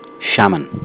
shah-man, not shay-man)